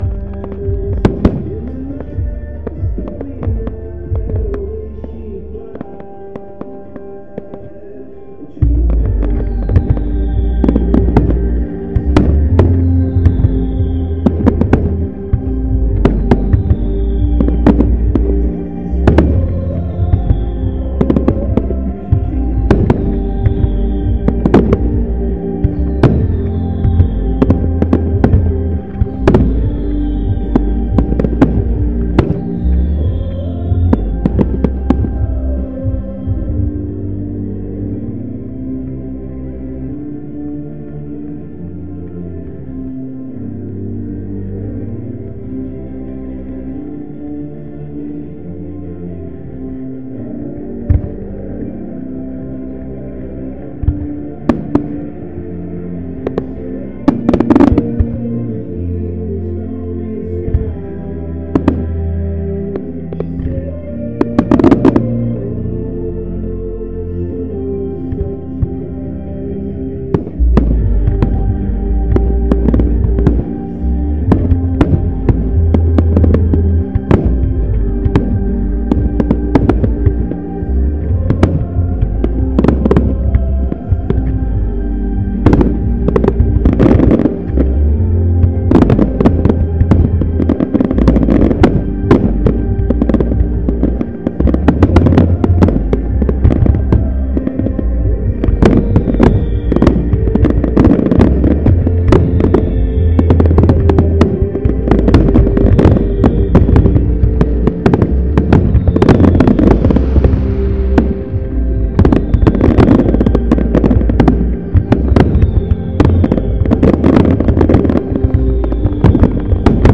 Firework Championship Finale. An explosive end to the evening.